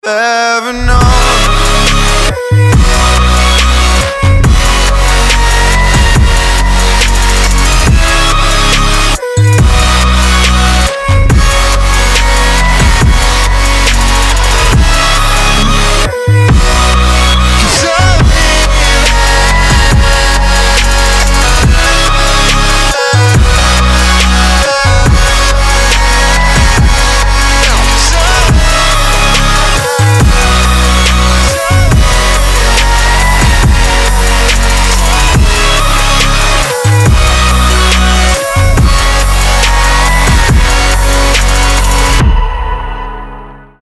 • Качество: 320, Stereo
громкие
Electronic
EDM
Trap
future bass